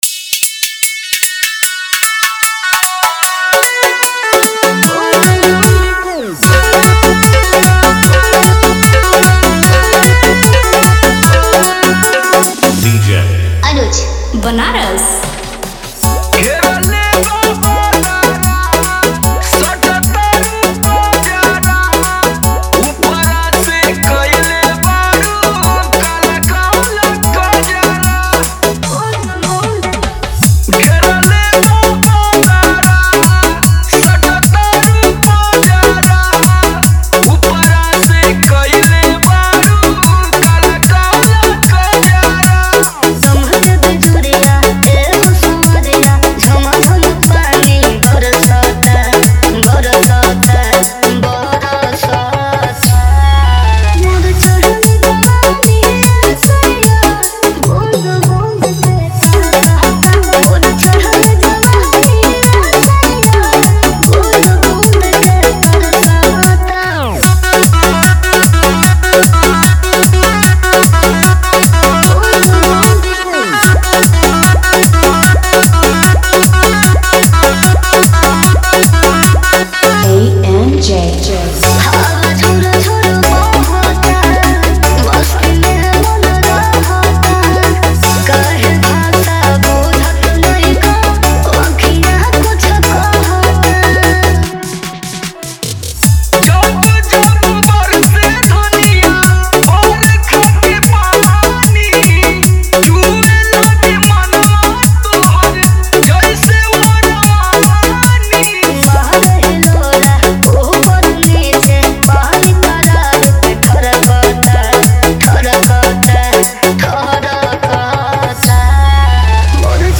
न्यू भोजपुरी डीजे सॉन्ग
वोकल: पॉपुलर भोजपुरी सिंगर
कैटेगरी: भोजपुरी डांस रीमिक्स, देसी रोमांस सॉन्ग